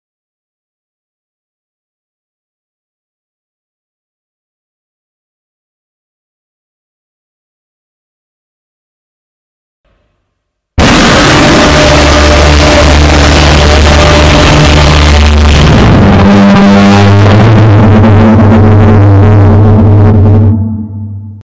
The Loudest Sound In Kaiju Sound Effects Free Download